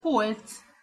Pronunciation Hu Polc (audio/mpeg)